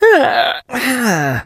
sandy_hurt_vo_06.ogg